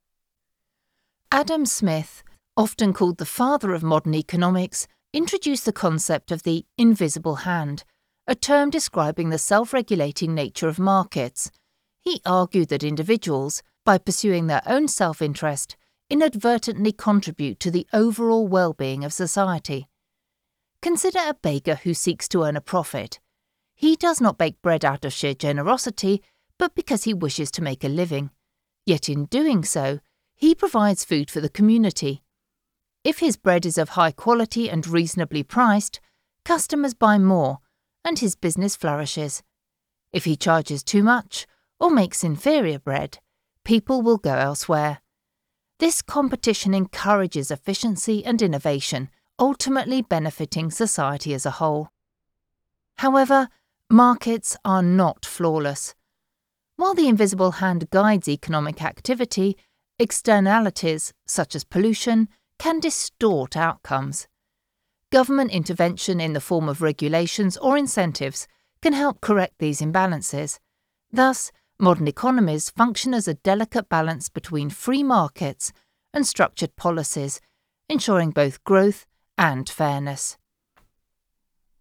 Female
I offer an RP British voice with excellent diction and an expressive, nuanced delivery.
My voice is warm, clear, and naturally engaging.
E-Learning
Economics Lesson.